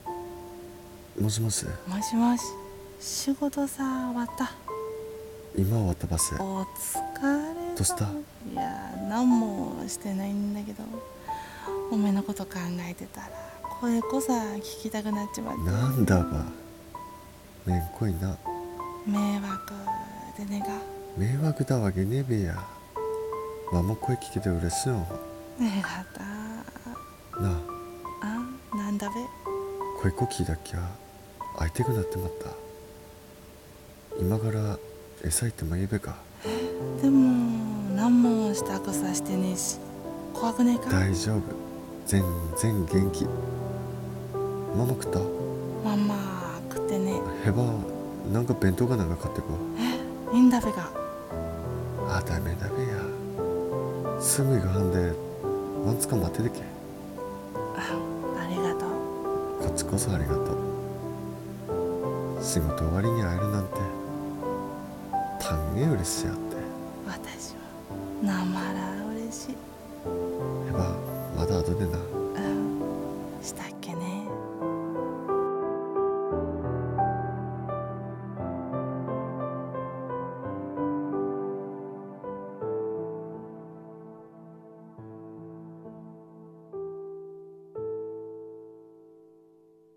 声劇【電話～声が聴きたくて～】 東北弁×微妙な北海道弁